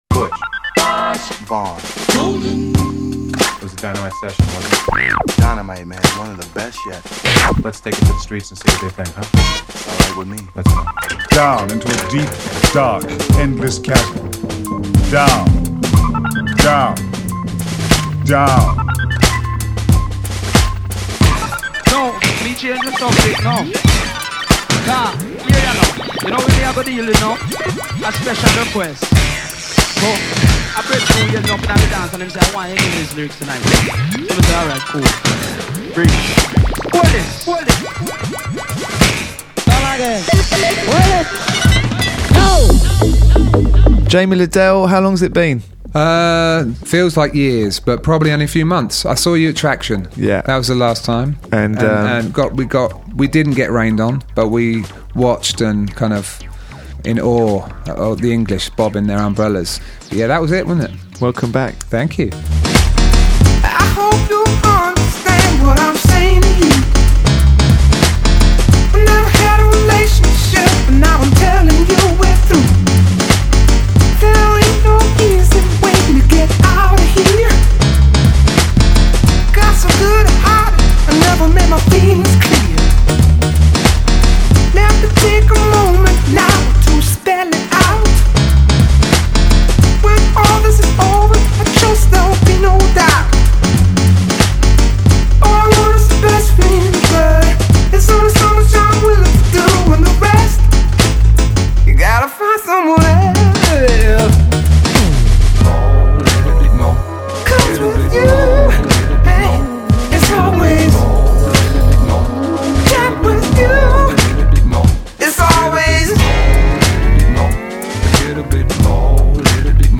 GILLES PETERSON - MUSIC AND CHAT ABOUT MUSIC -what else!
His new album ‘Jamie Lidell’ finds him in synth heaven, inspired by the likes of Jam & Lewis, George Clinton and Cameo, and on a recent trip to London he joined Gilles Peterson in the basement for a good ol’ chat about the past, present and future plans.